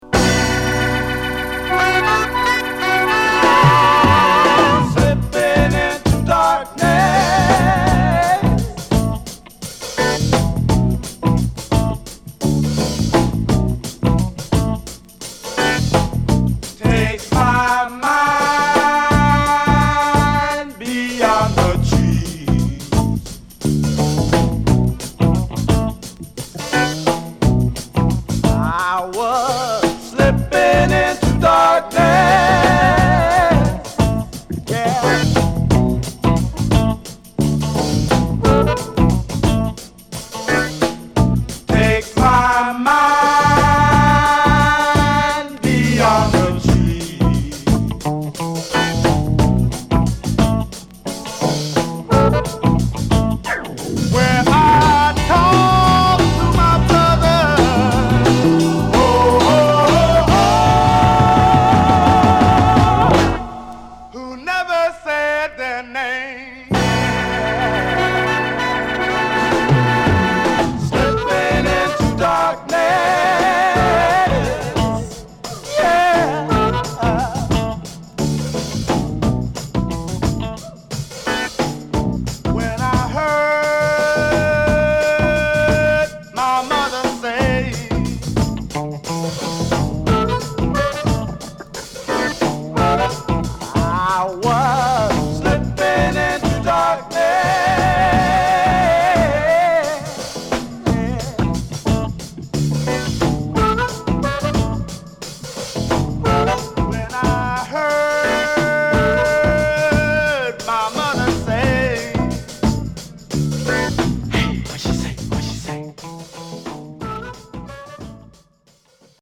(Mono)